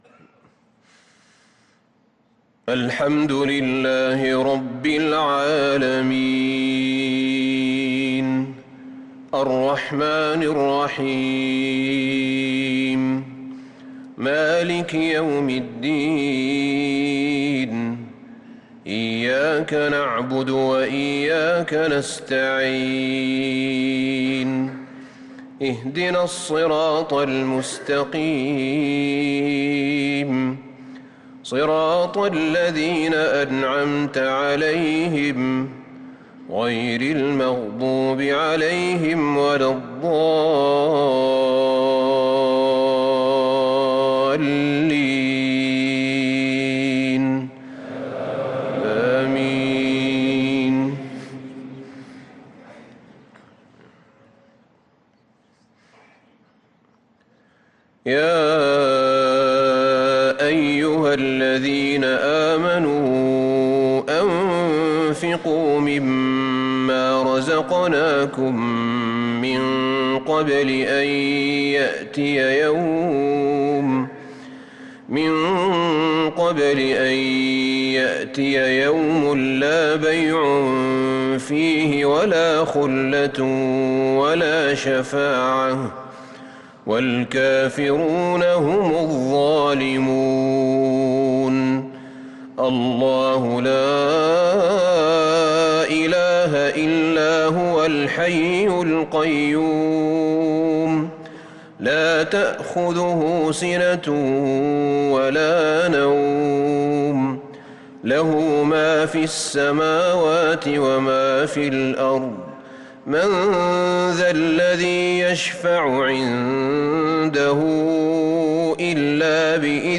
صلاة العشاء للقارئ أحمد بن طالب حميد 18 شعبان 1445 هـ
تِلَاوَات الْحَرَمَيْن .